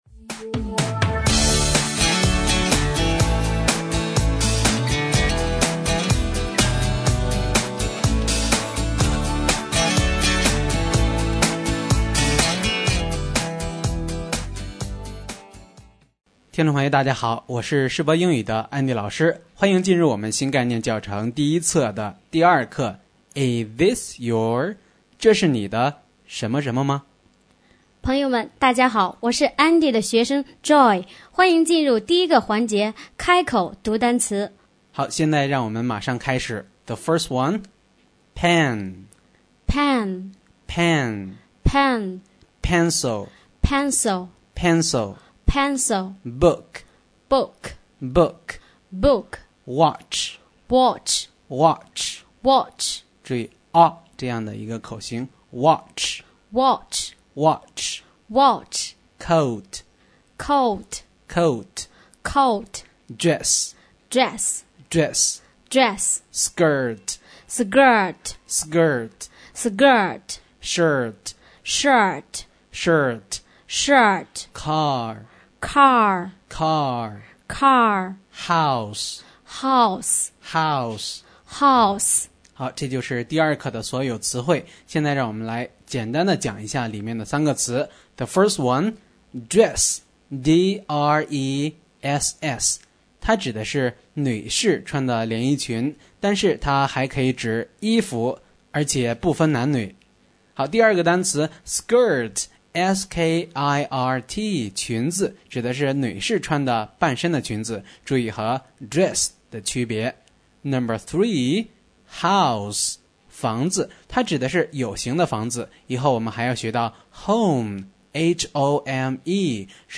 新概念英语第一册第2课【开口读单词】